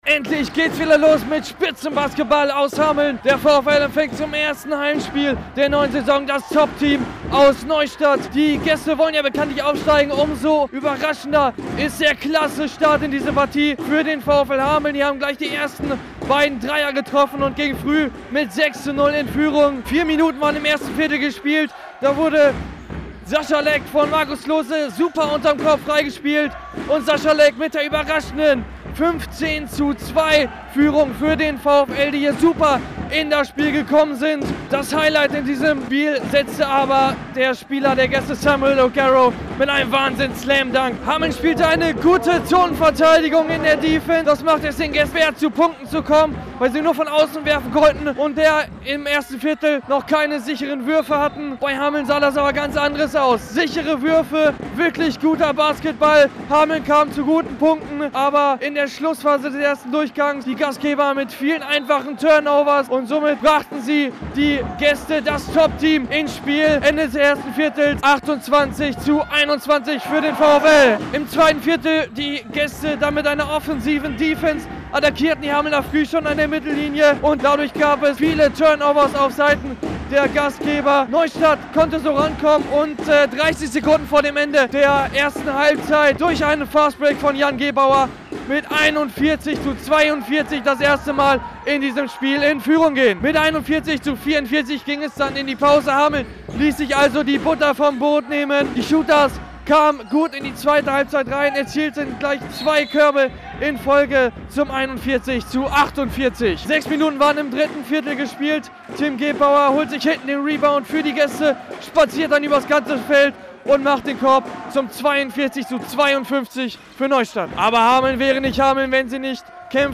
RADIO AKTIV - Reportage